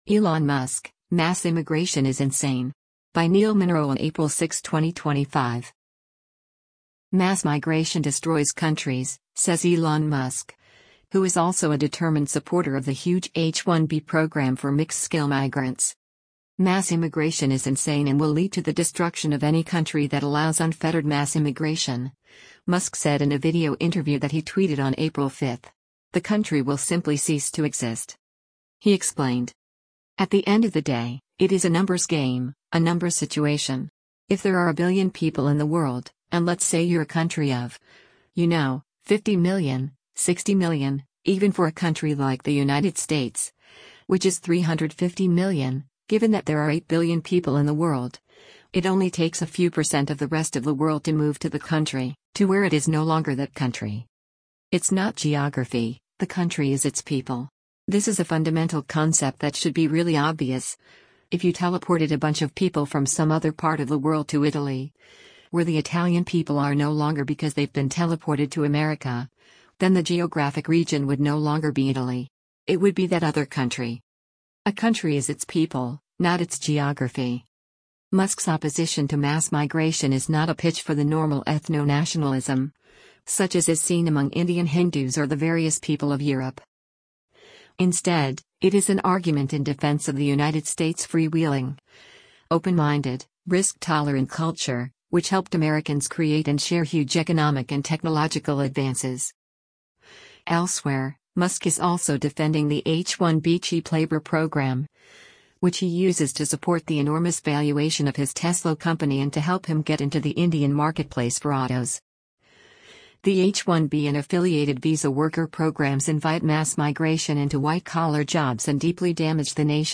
“Mass immigration is insane and will lead to the destruction of any country that allows unfettered mass immigration,” Musk said in a video interview that he tweeted on April 5.